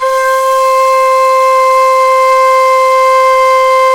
NYE FLUTE03R.wav